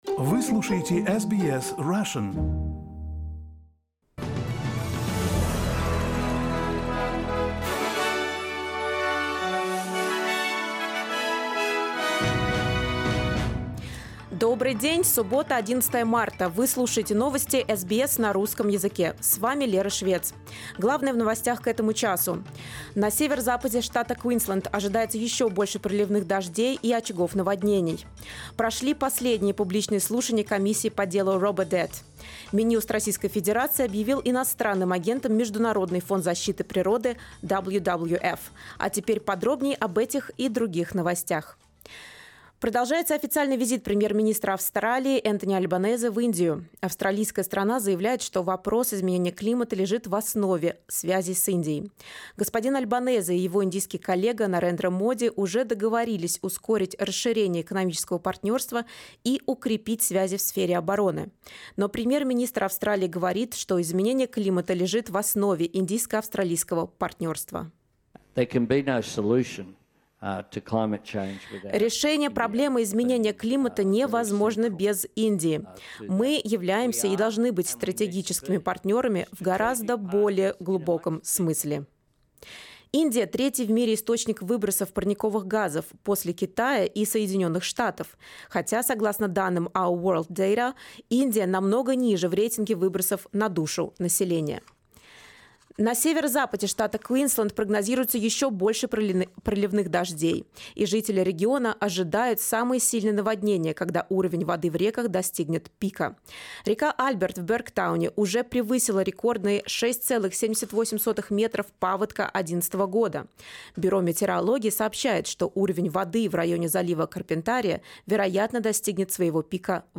SBS news in Russian — 11.03.2023